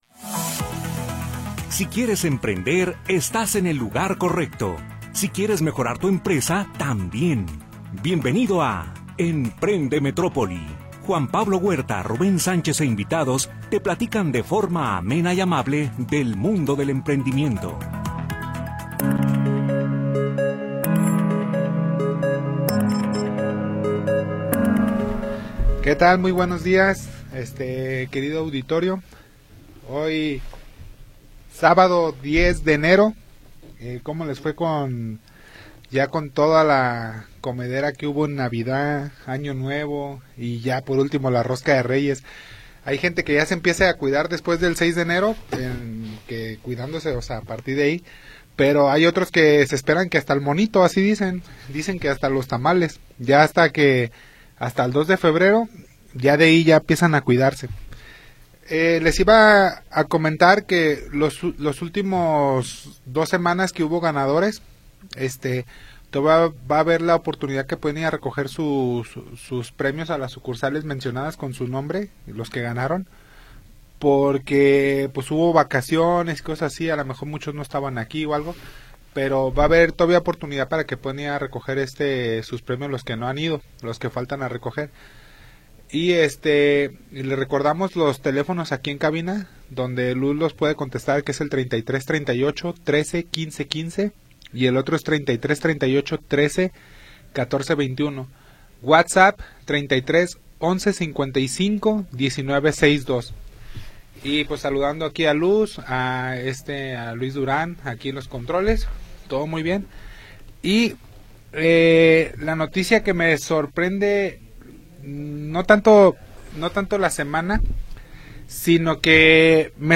e invitados te platican de forma amable y amena acerca del mundo del emprendimiento.